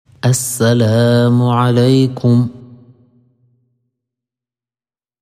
Cliquez ci-dessous pour écouter la prononciation du salam:
Salam.mp3